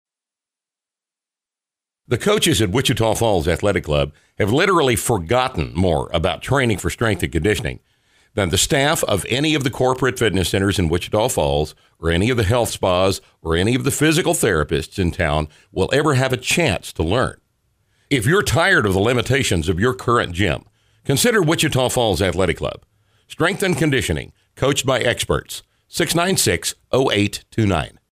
Radio spot: Strength & Conditioning at WFAC.